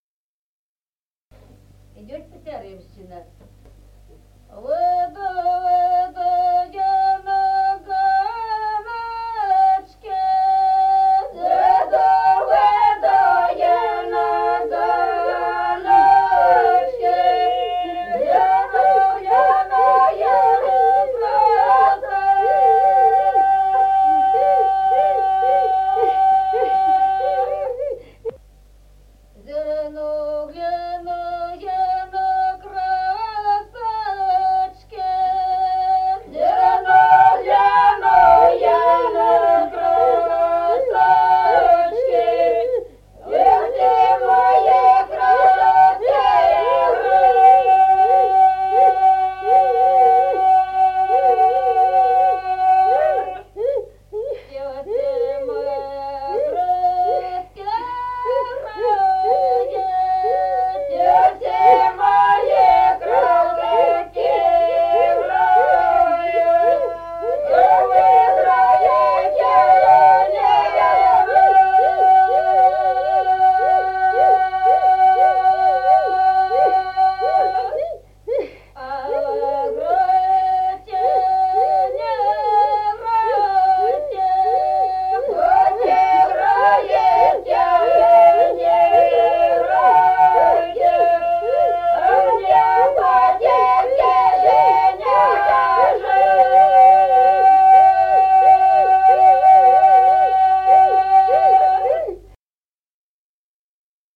с. Остроглядово.